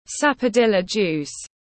Nước ép hồng xiêm tiếng anh gọi là sapodilla juice, phiên âm tiếng anh đọc là /ˌsæ.pə.ˈdɪ.lə ˌdʒuːs/